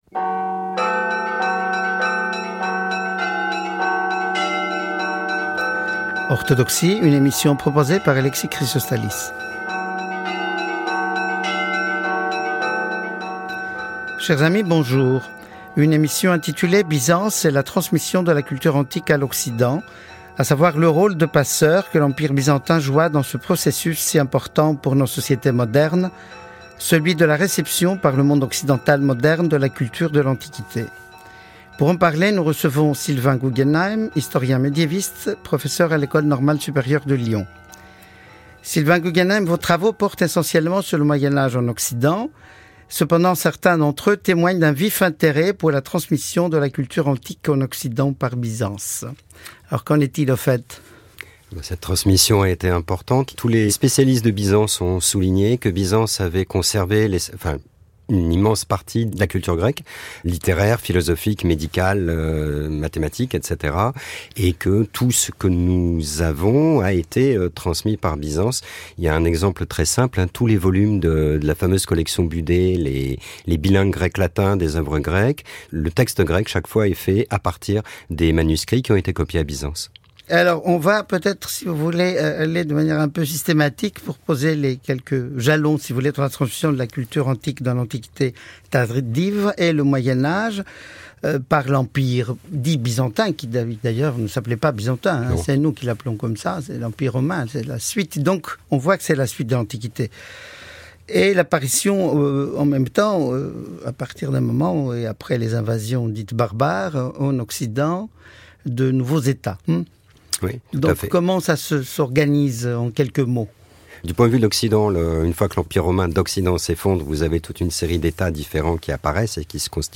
Entretien sur le rôle capital qu'a joué l'Empire dit "byzantin", c'est-à-dire l'Empire romain d'Orient, dans la transmission de la culture antique à l'Occident, en particulier aux cours royales et aux abbayes de l'Europe à l'époque romane.